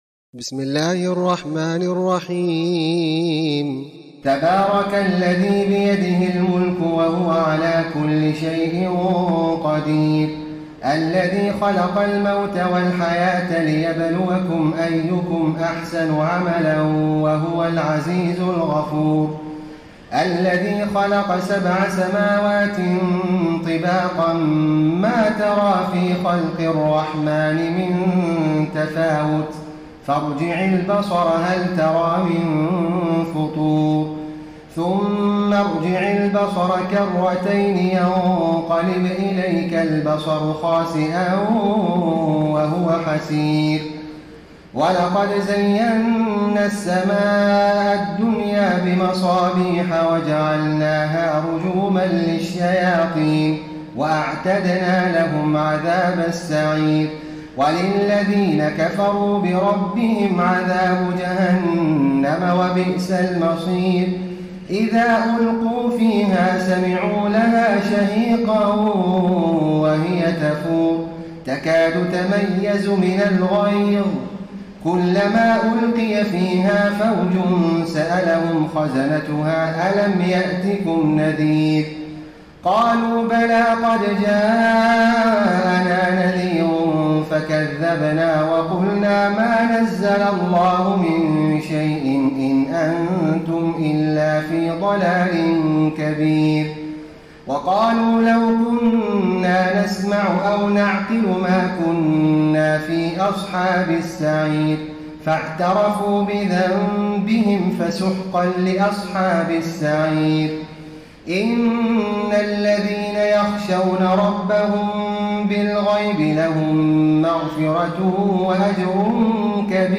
تراويح ليلة 28 رمضان 1433هـ من سورة الملك الى نوح Taraweeh 28 st night Ramadan 1433H from Surah Al-Mulk to Nooh > تراويح الحرم النبوي عام 1433 🕌 > التراويح - تلاوات الحرمين